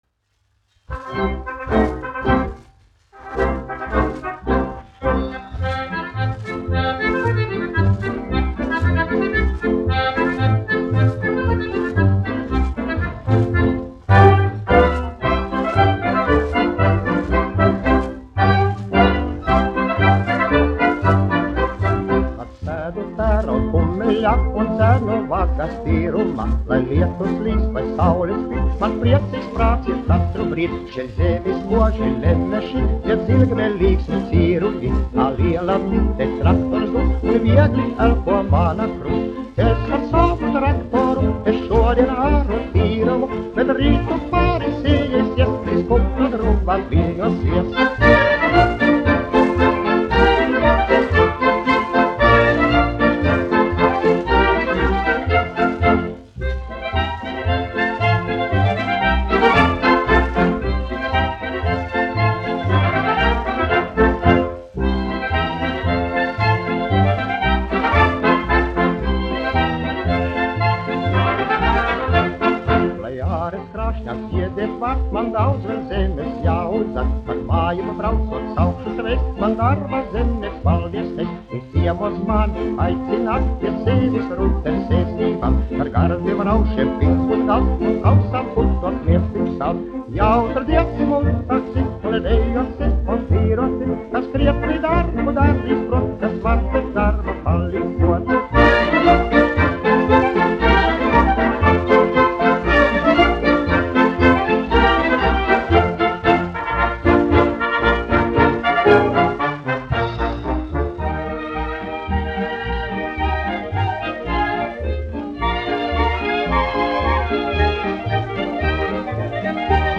1 skpl. : analogs, 78 apgr/min, mono ; 25 cm
Polkas
Populārā mūzika
Skaņuplate